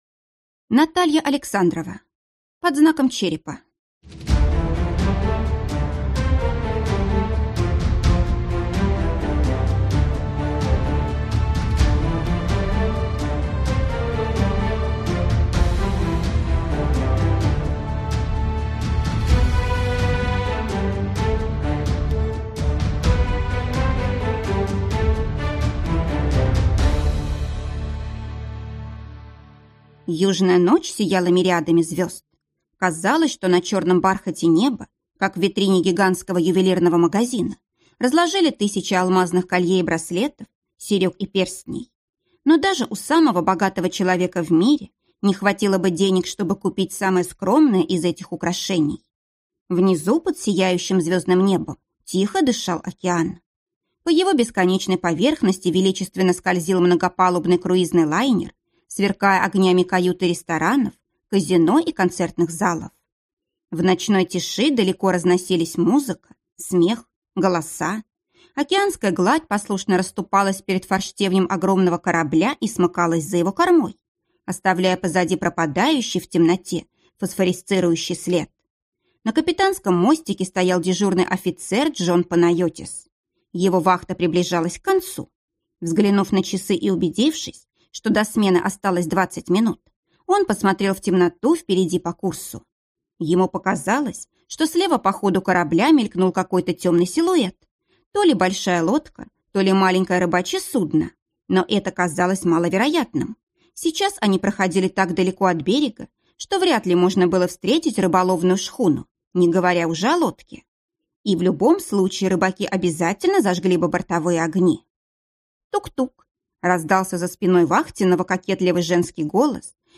Аудиокнига Под знаком черепа | Библиотека аудиокниг
Прослушать и бесплатно скачать фрагмент аудиокниги